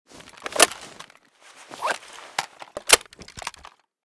ash12_reload.ogg.bak